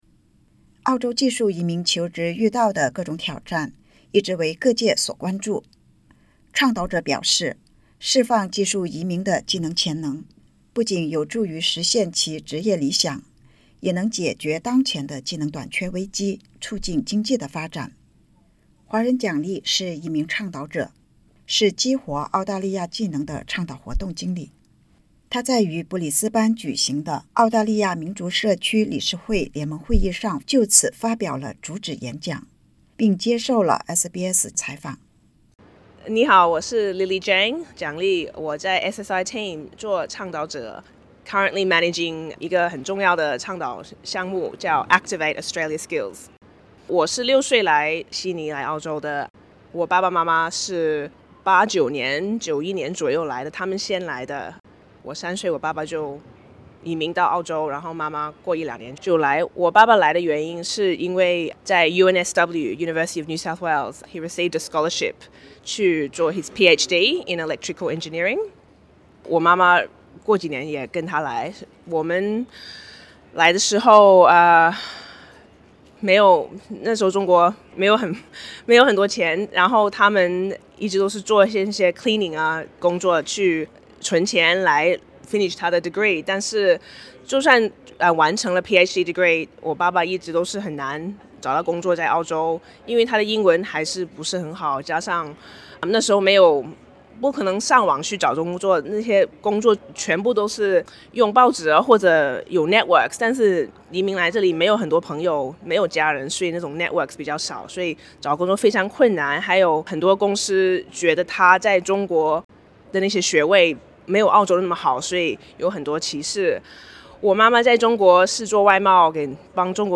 澳大利亚华人技术移民在接受SBS普通话采访时提到，她们在求职时遇到海外学历和资质难获认可、英语问题、社交网络受限、缺乏本地工作经验以及澳中不同职场文化差异等挑战。（点击音频收听详细采访）